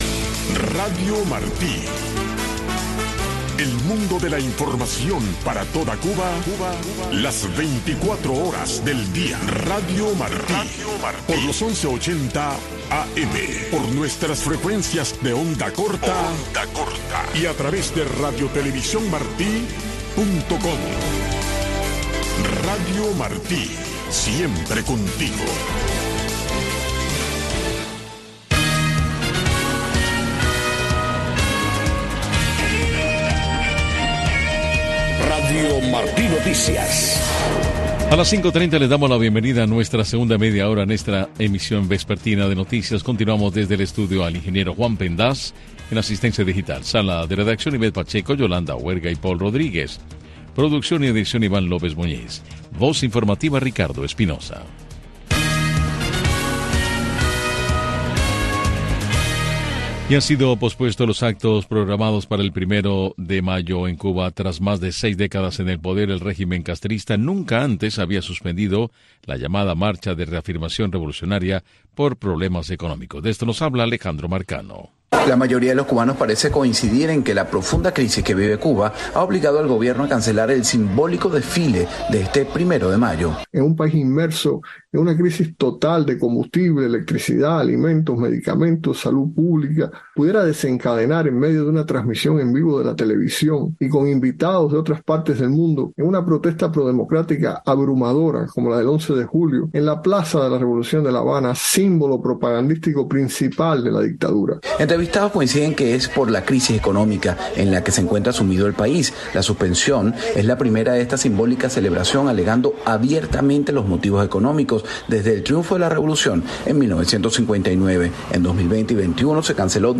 Noticiero de Radio Martí 5:00 PM | Segunda media hora